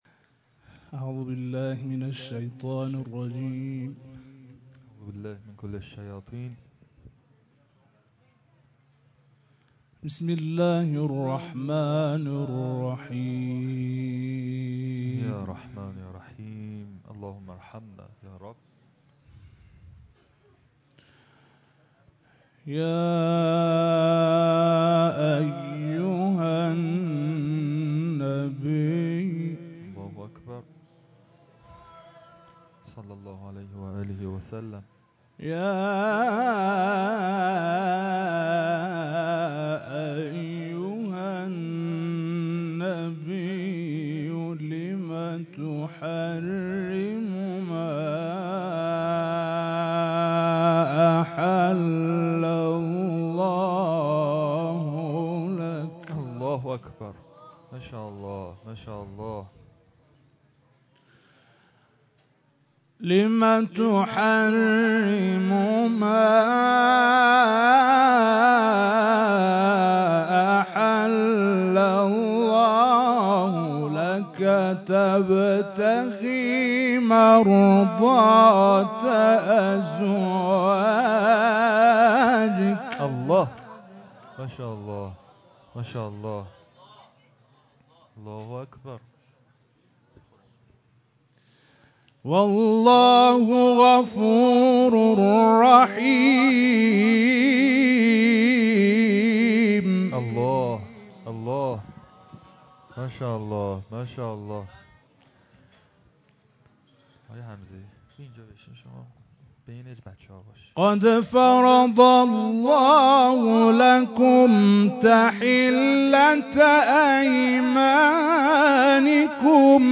据伊通讯社报道在盖迪尔·胡姆节，法蒂玛·扎赫拉侯赛因纪念堂举行了《古兰经》亲近会活动。
所有标签: 古兰经 诵读 伊朗 盖迪尔节